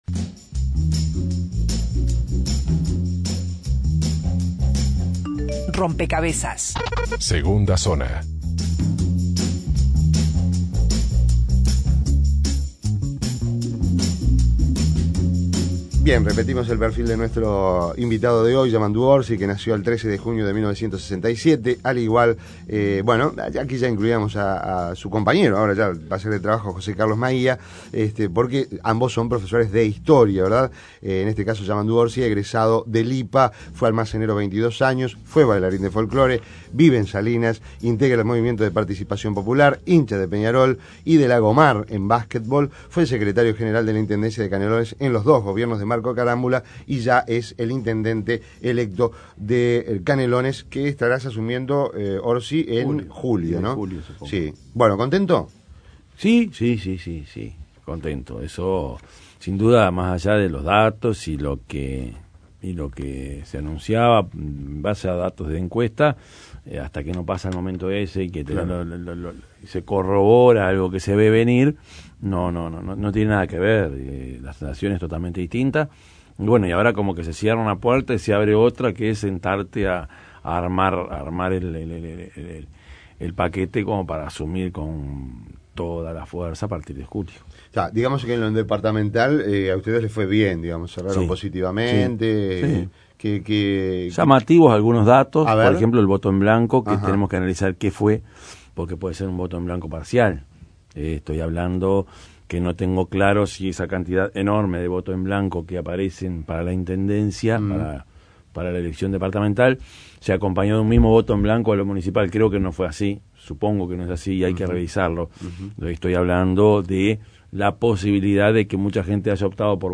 Orsi tomó nota de las inquietudes de los oyentes del programa sobre tránsito, saneamiento, drenaje fluvial, la basura, entre otros temas y aseguró que firmó en contra de la reapertura de la llamada "Cantera de la muerte"; en la localidad de Joaquín Suárez.